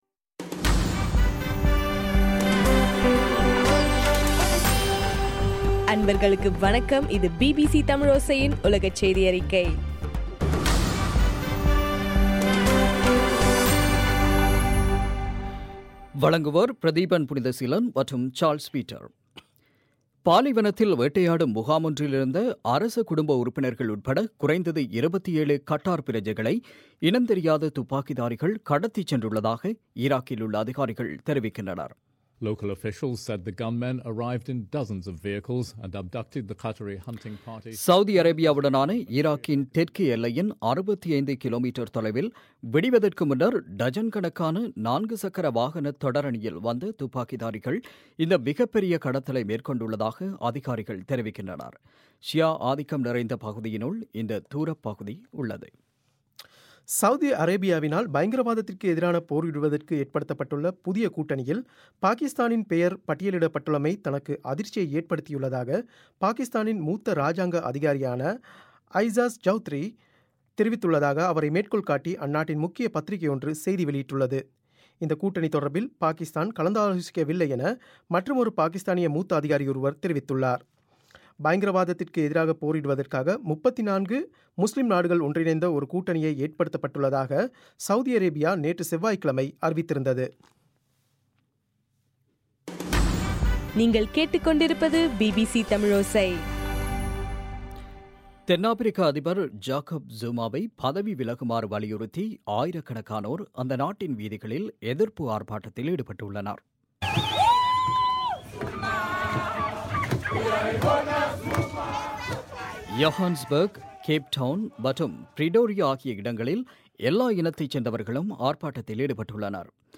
இன்றைய (டிசம்பர் 16) பிபிசி தமிழோசை செய்தியறிக்கை